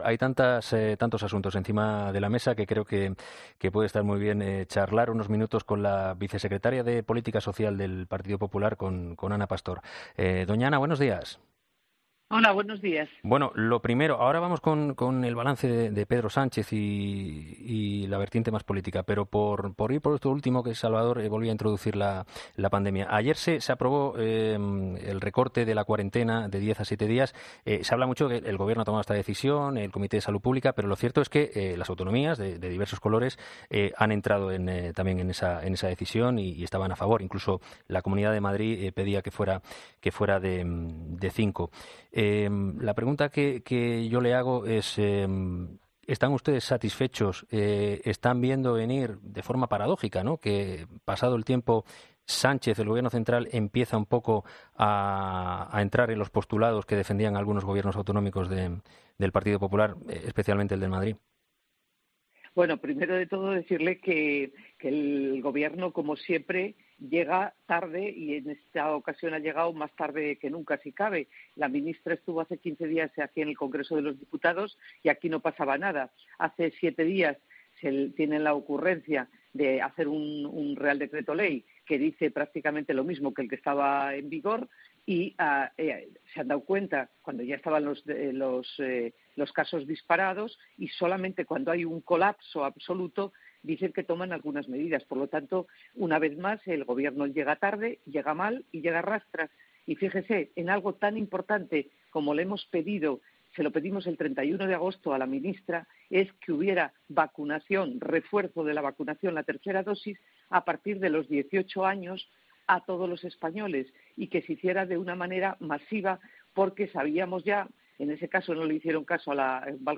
En una entrevista este jueves en 'Herrera en COPE', la exministra de Sanidad ha sido muy crítica con el balance del año que dio ayer el presidente del Gobierno durante su comparecencia ante los medios en Moncloa.